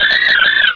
Cri de Négapi dans Pokémon Rubis et Saphir.